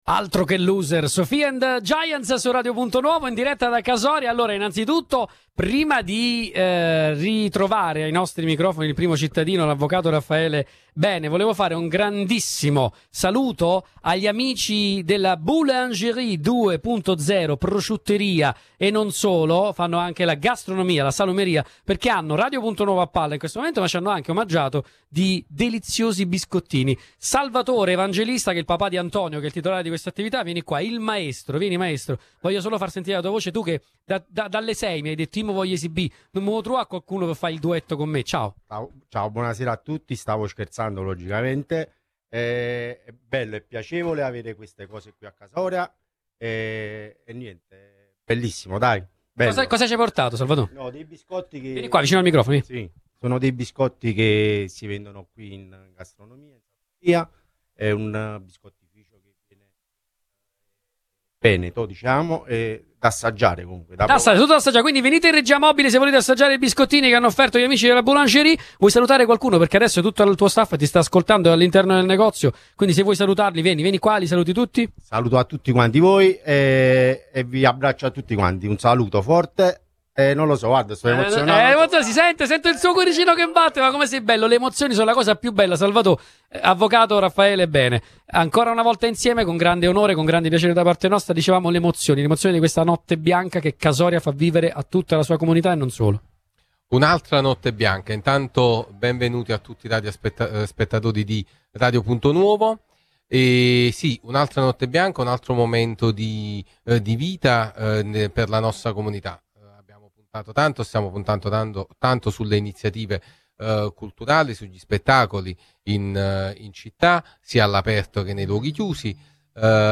Sotto le stelle di una serata baciata dal bel tempo, Radio Punto Nuovo ha raccontato in diretta ogni istante di un evento che ha trasformato il cuore dello shopping in un’arena di festa, tra le bolle di sapone per i più piccoli, le eccellenze gastronomiche locali (come i dolci omaggi della Boulangerie 2.0) e le grandi performance live.
Ai nostri microfoni, il primo cittadino Raffaele Bene ha tracciato un bilancio che va ben oltre i festeggiamenti: “Abbiamo puntato tanto sulle iniziative culturali e sugli spettacoli, sia all’aperto che nei luoghi chiusi. Questa è una serata magica dedicata al divertimento sano”.